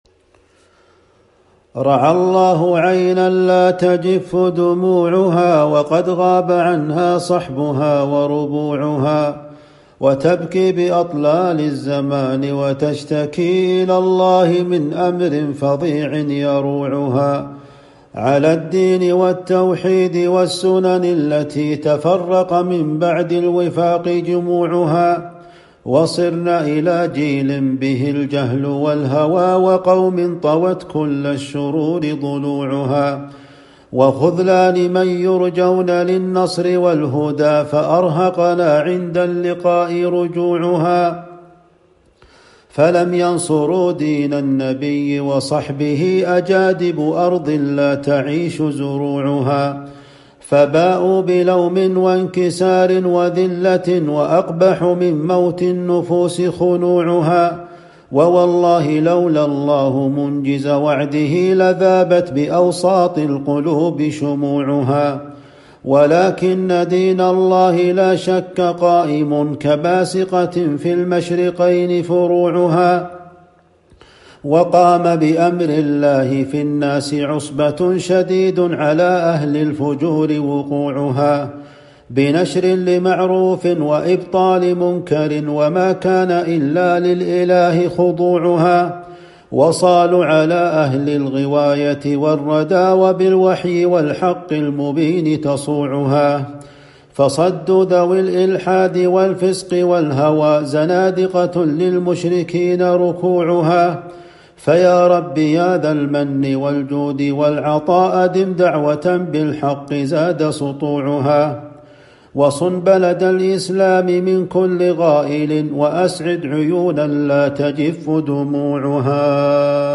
وأقبح من موت النفوس خنوعها .. قصيدة من نظم وصوت الشيخ